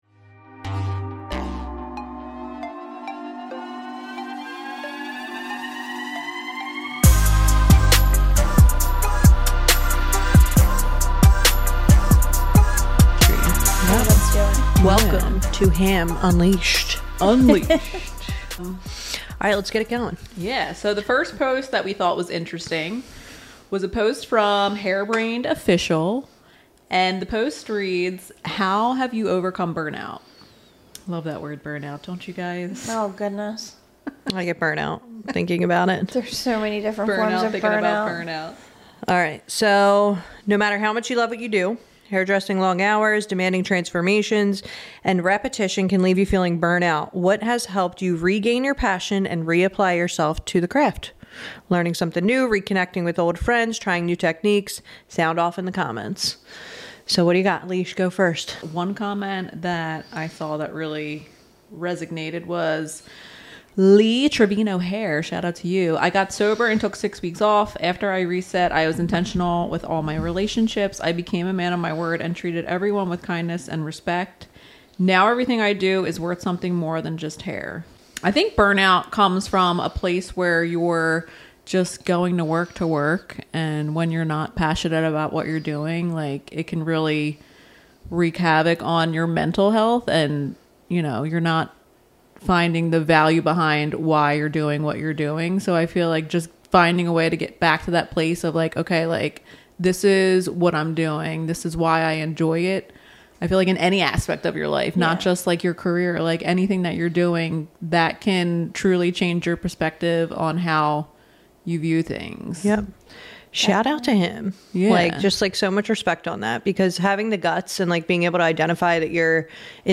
Join us for an unfiltered conversation as we tackle some of the most controversial and pressing issues facing salon professionals today.